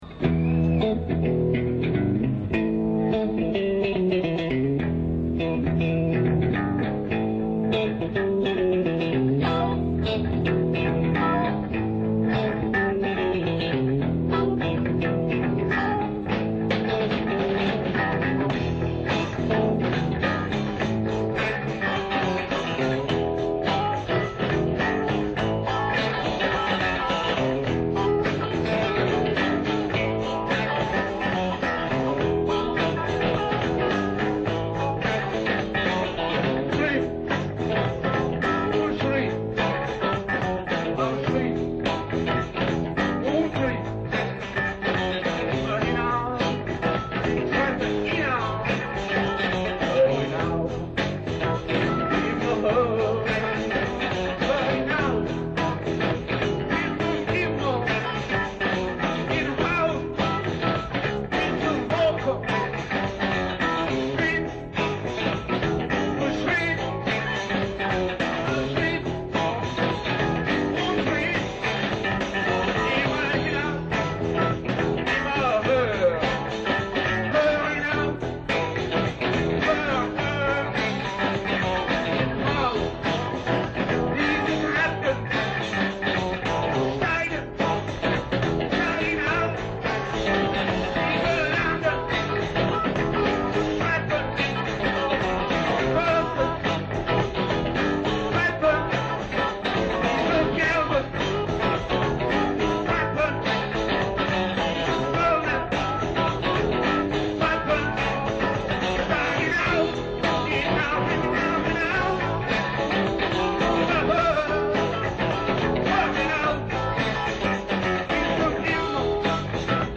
voce e chitarra
sviluppiamo un fantastico repertorio rock in italiano, inglese e tedesco